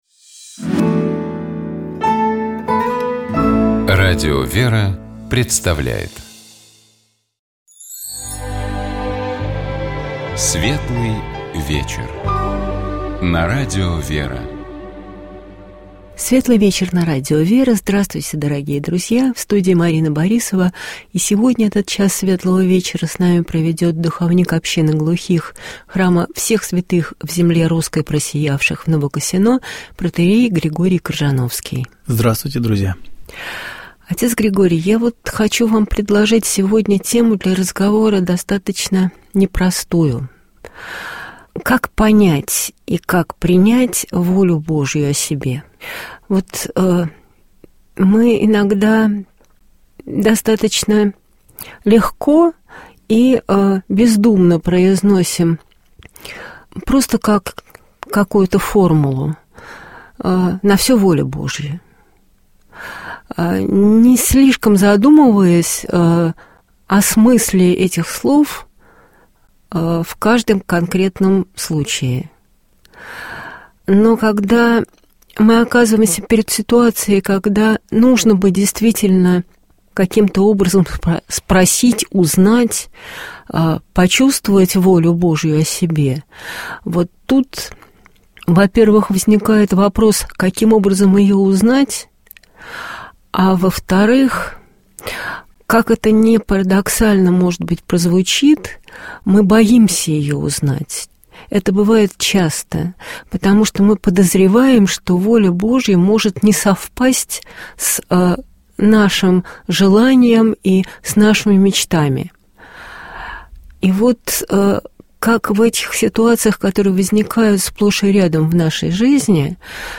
Богослужебные чтения - Радио ВЕРА